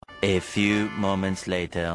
Sound Effects
A Few Moments Later Sponge Bob Sfx Fun